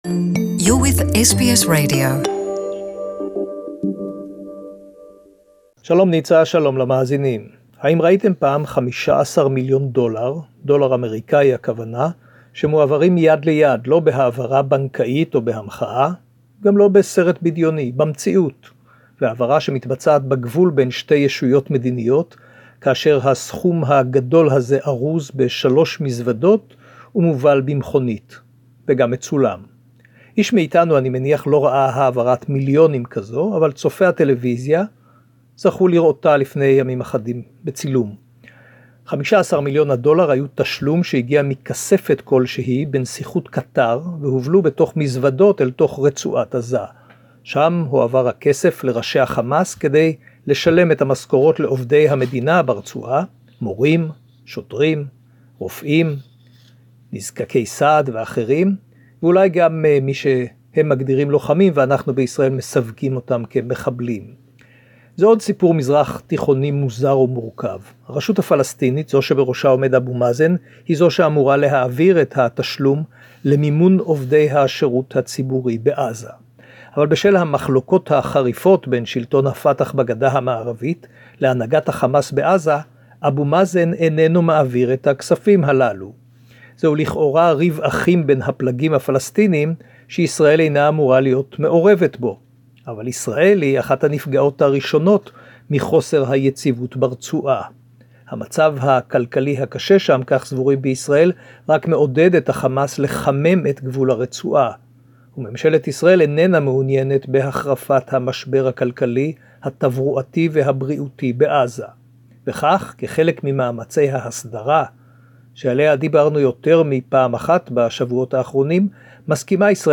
SBS Correspondent